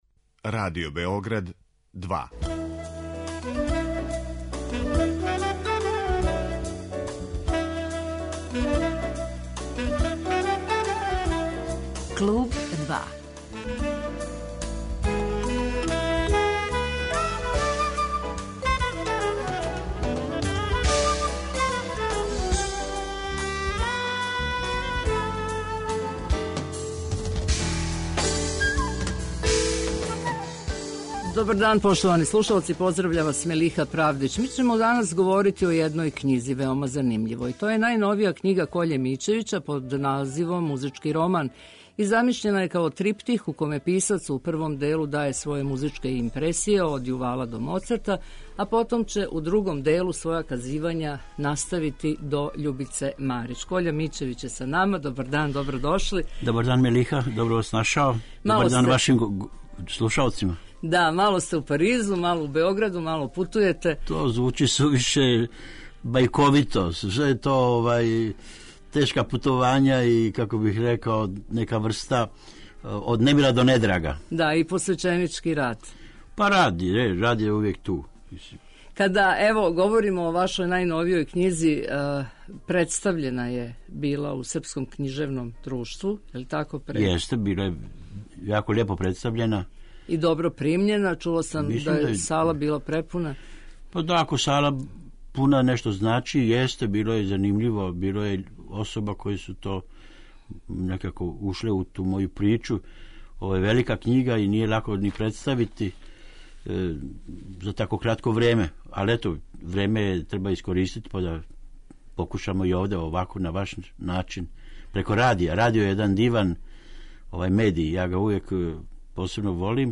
Гост 'Клуба 2' је Коља Мићевић, песник и преводилац.